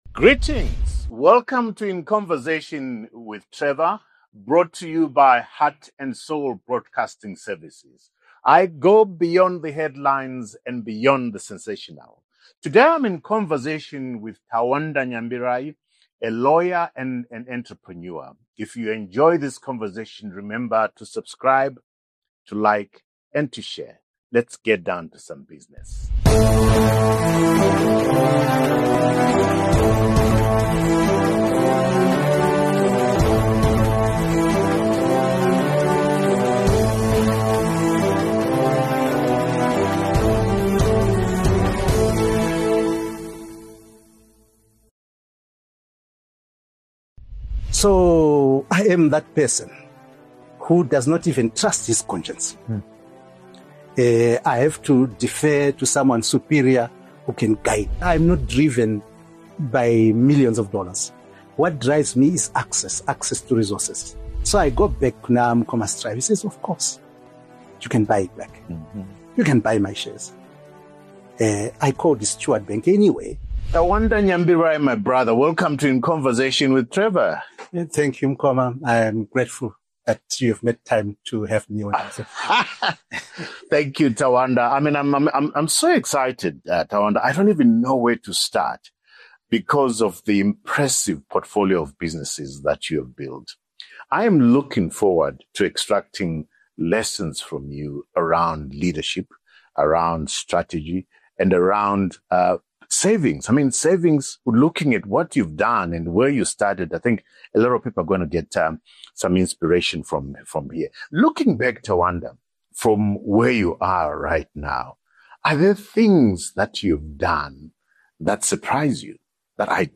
Zimbabwean entrepreneur and newspaper publisher Trevor Ncube sits down with various high-profile guests in a series of candid, hard-hitting conversations.